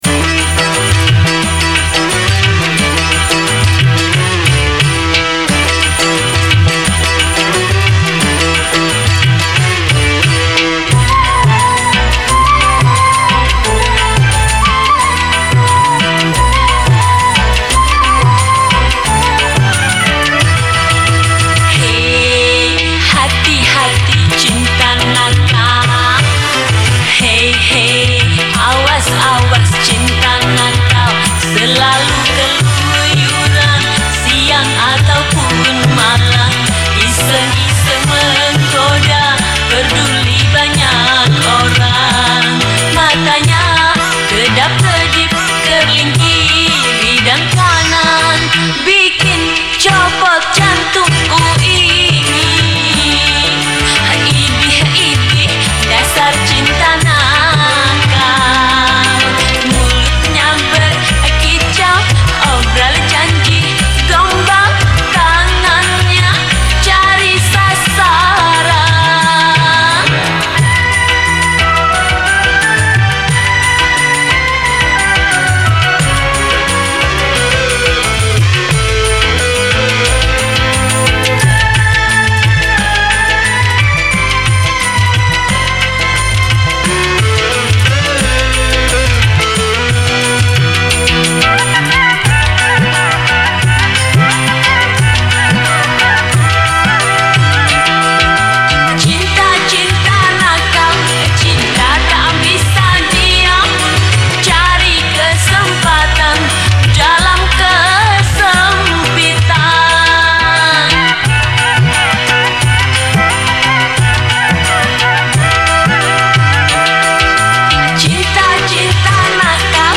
Instrumen                                     : Vokal